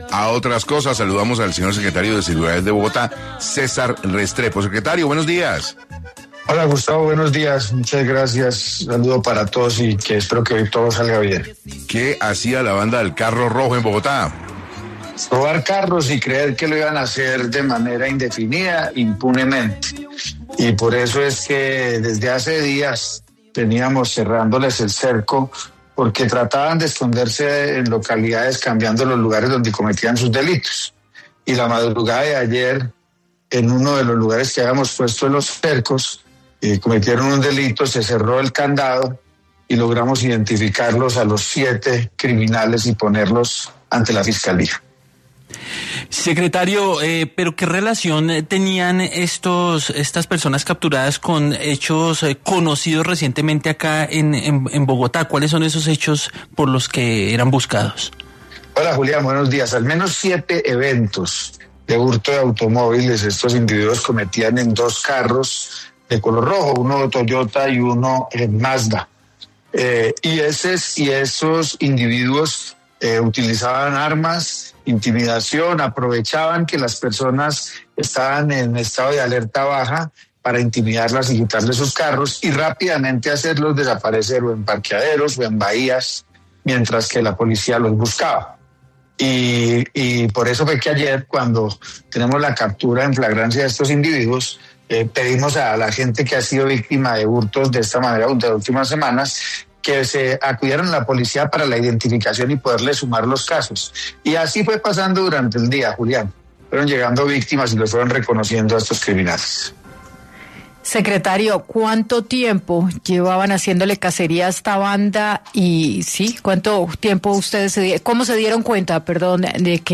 En entrevista con 6AM de Caracol Radio, el secretario de seguridad, César Restrepo aseguró que: : “Desde hace días veníamos cerrándoles el cerco porque trataban de esconderse en localidades cambiando los lugares donde cometían sus delitos. Y en uno de los lugares que habíamos puesto en los cercos, cometieron un delito, se cerró el candado y logramos identificar a los siete criminales y ponerlos ante la fiscalía”.